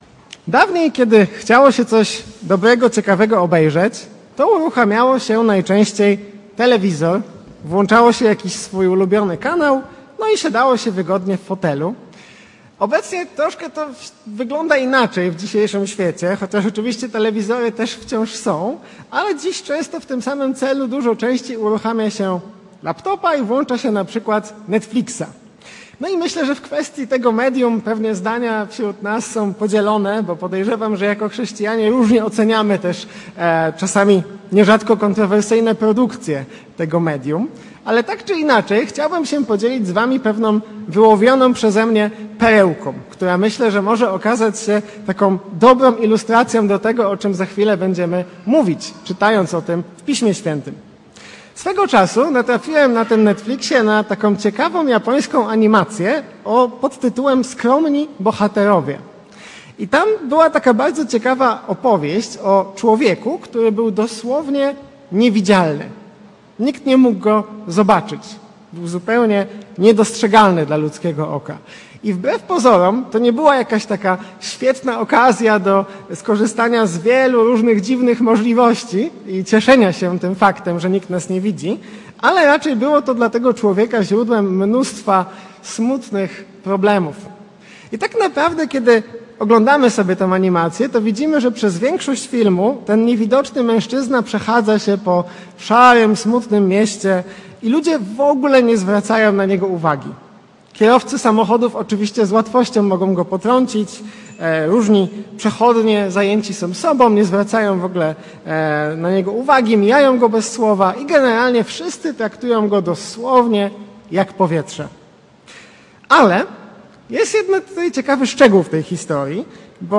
Kaznodzieja